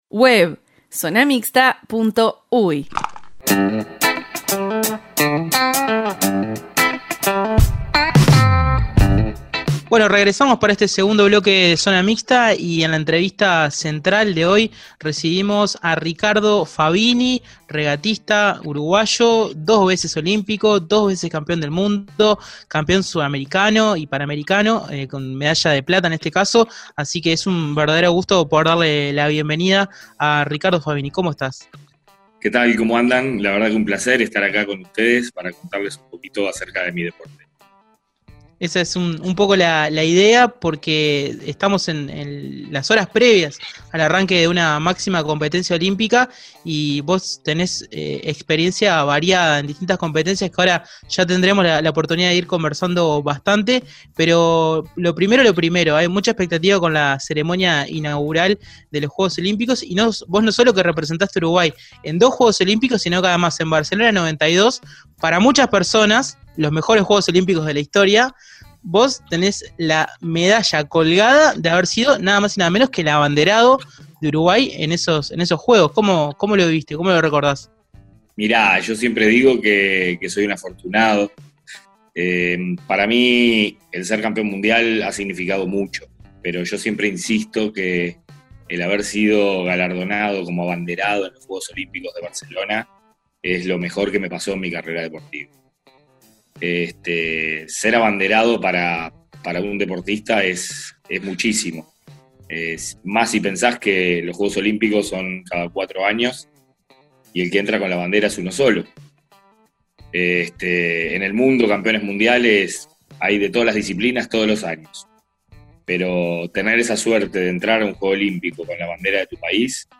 Zona Mixta: entrevista